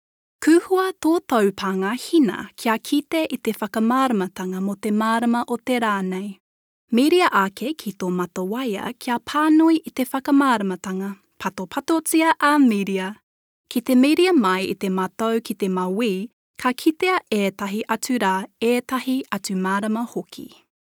Teenager, Young Adult, Adult
new zealand | natural